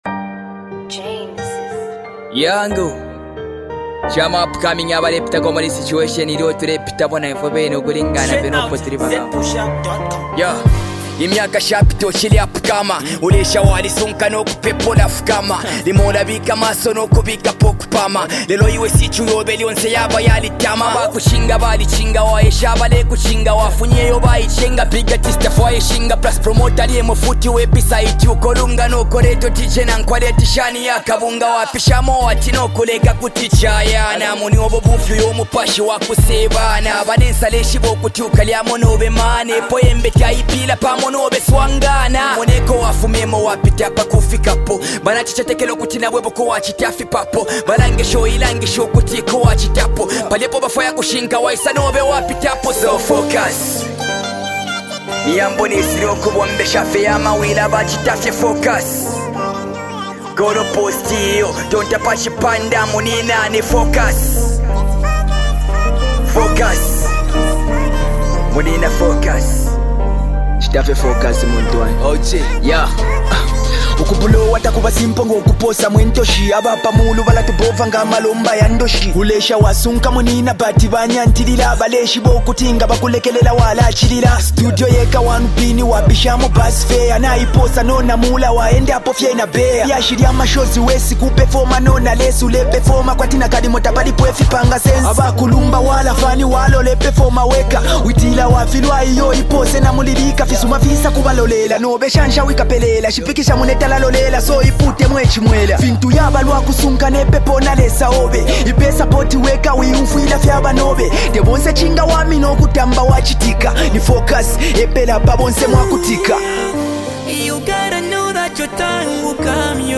FREE BEATS